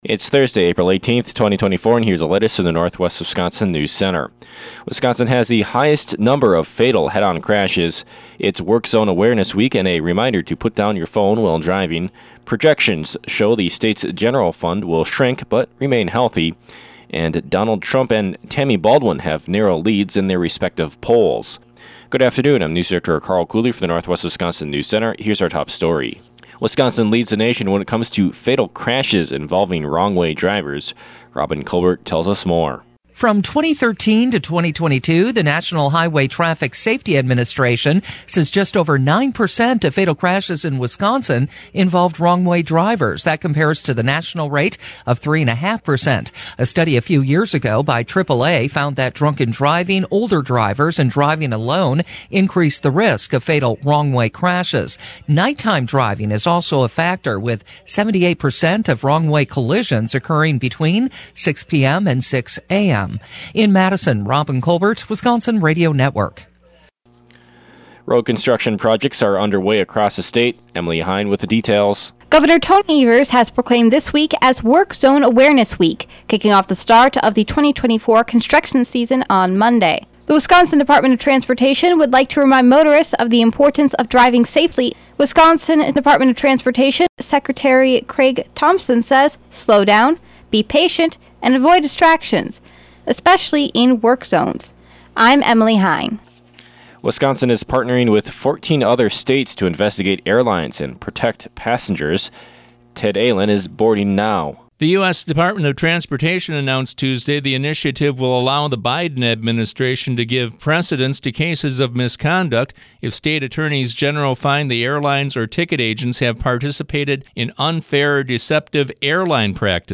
PM NEWSCAST – Thursday, April 18, 2024 | Northwest Builders, Inc.